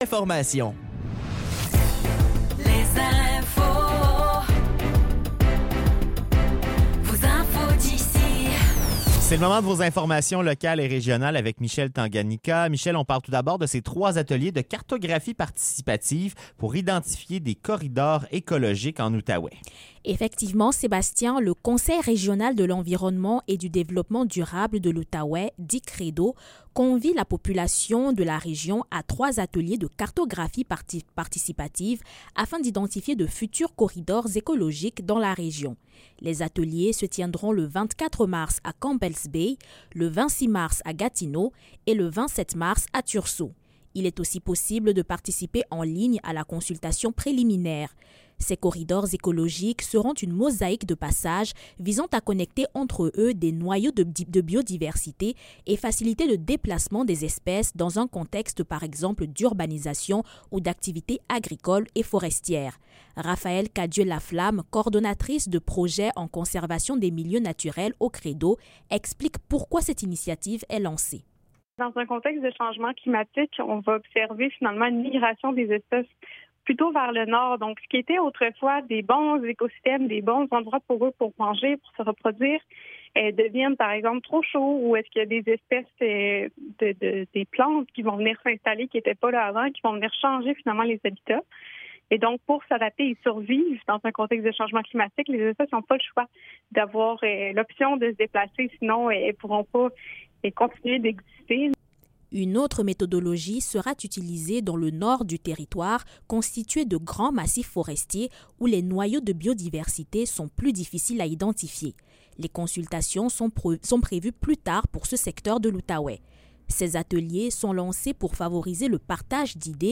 Nouvelles locales - 21 mars 2025 - 16 h